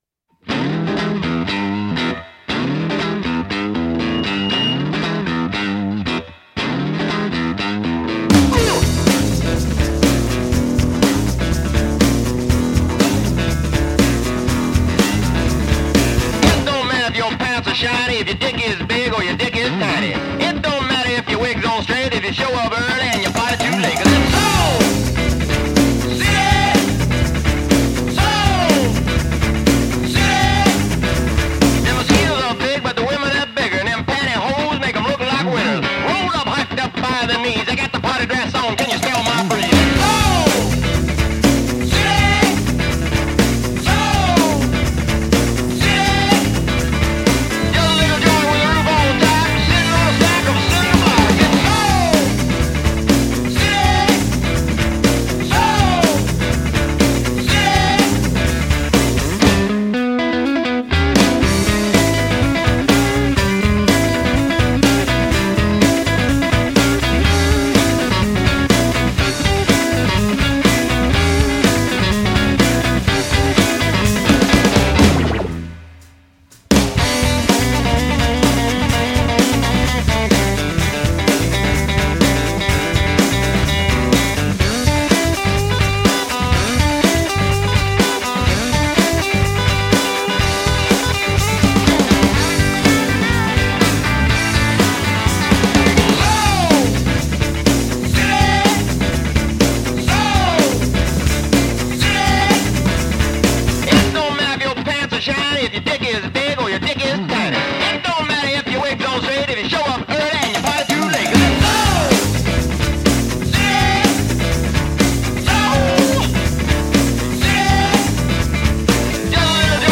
rousing soul-and-rockabilly hybrid